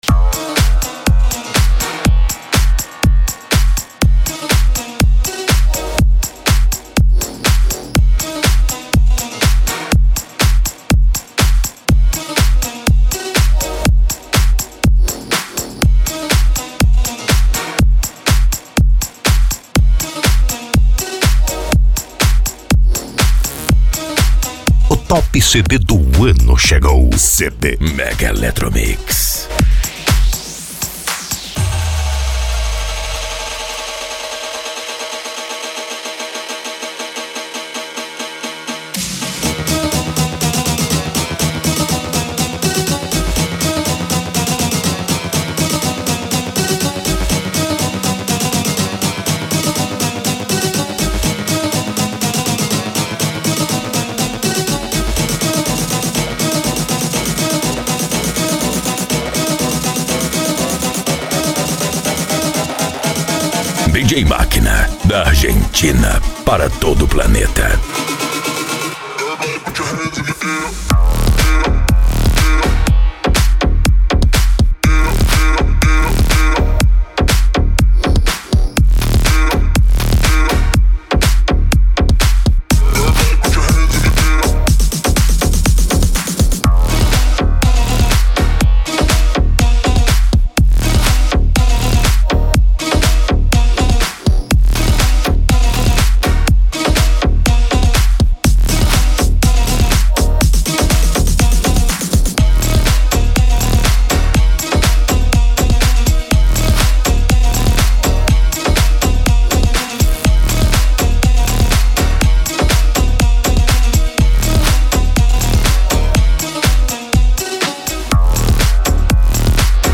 Electro House
Minimal
Psy Trance
Remix